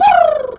pigeon_2_stress_01.wav